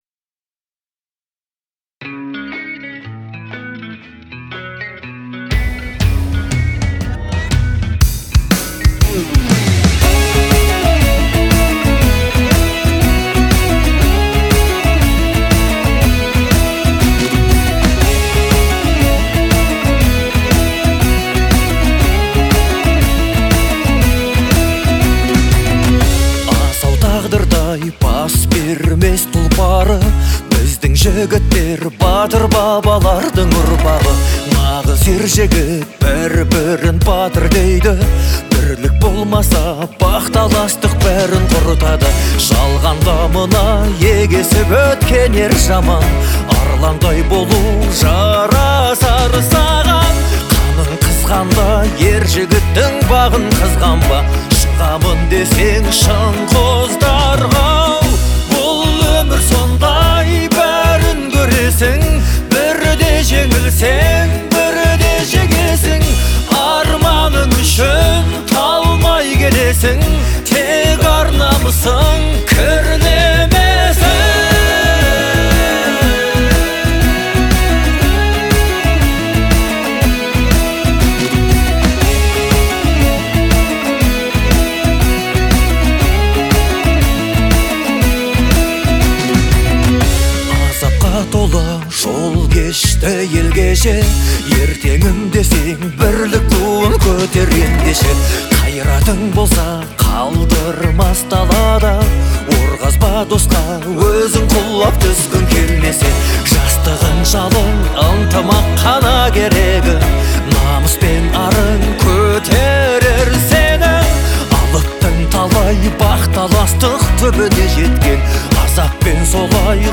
с большой эмоциональной отдачей